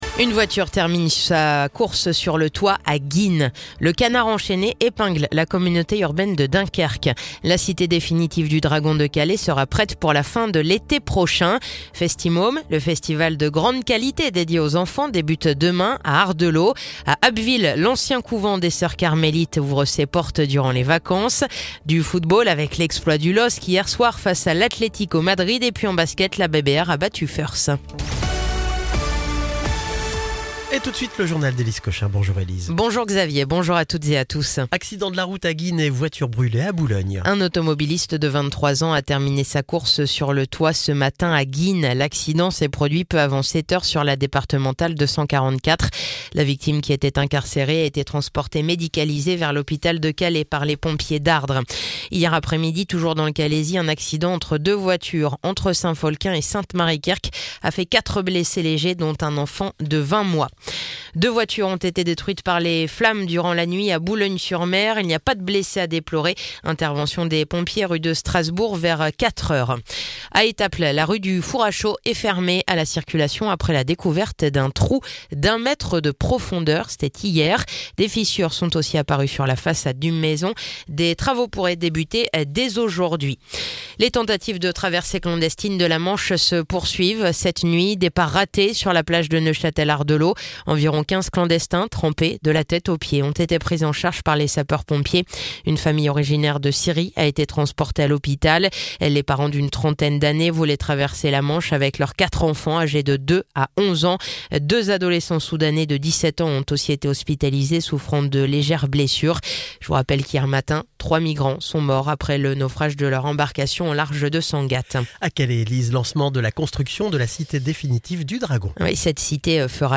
Le journal du jeudi 24 octobre